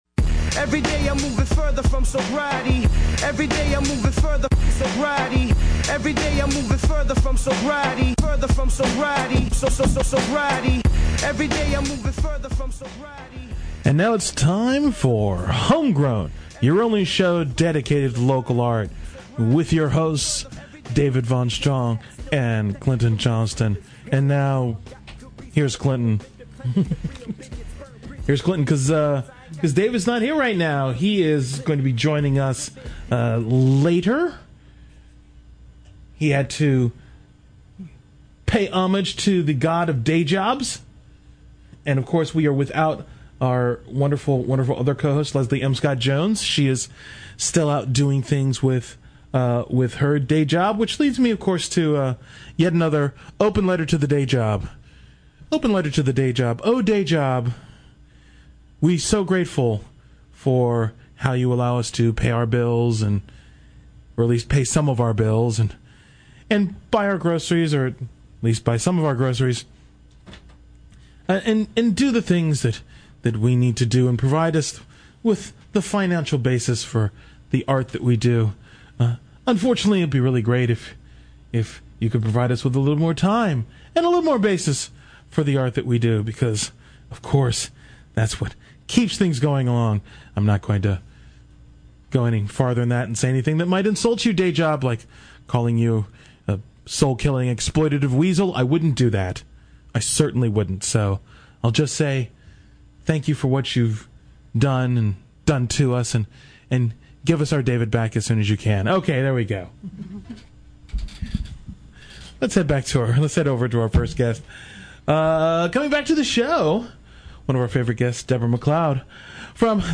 She reads a little from her work, and we fall into talking about the importance of and danger in telling personal stories and how memoirs can be so much more than old politicians rewriting their histories or celebrity tell-alls.